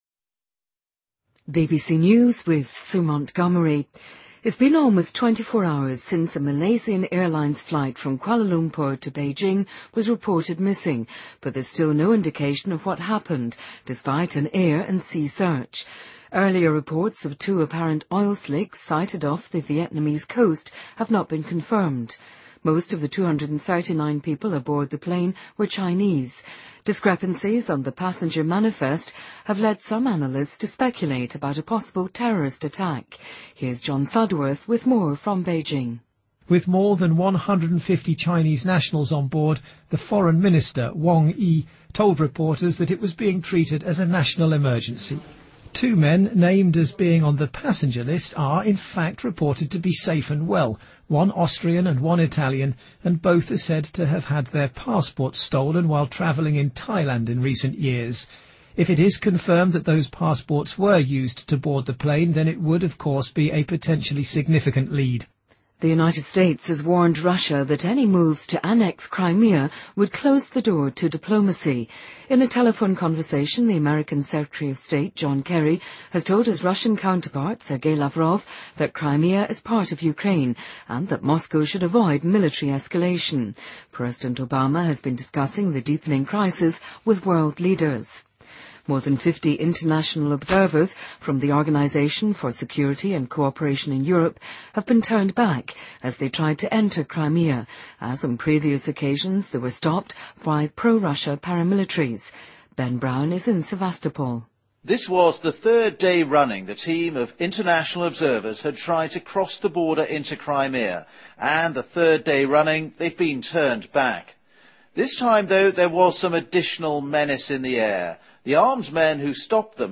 BBC news,2014-03-09